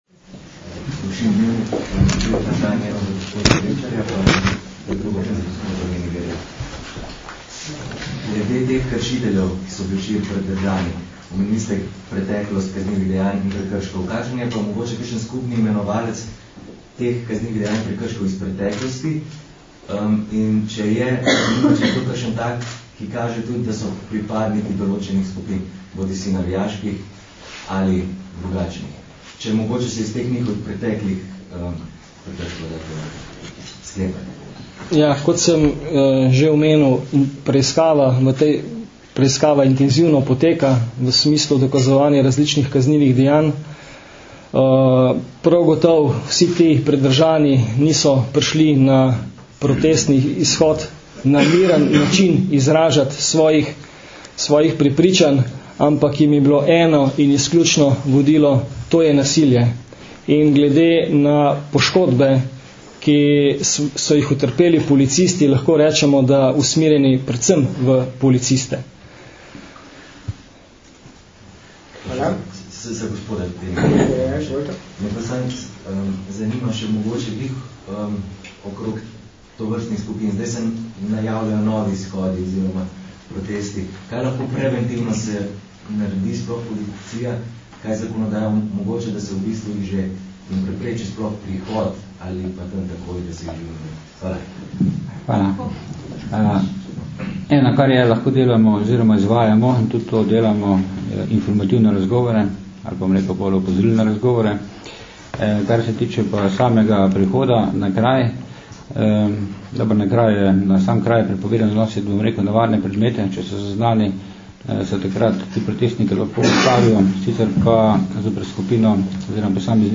Podrobnosti včerajšnjih dogodkov in policijskih ukrepov sta na današnji novinarski konferenci predstavila generalni direktor policije Stanislav Veniger in direktor Policijske uprave Ljubljana mag. Stanislav Vrečar.
Novinarska vprašanja in odgovori (mp3)
novinarska_vprasanja.mp3